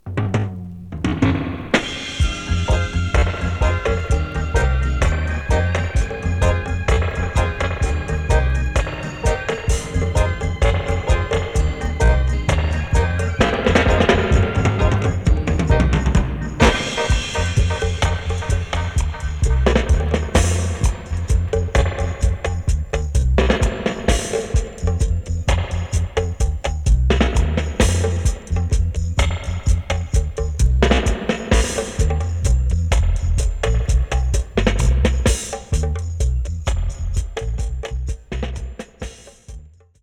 Genre: Reggae, Roots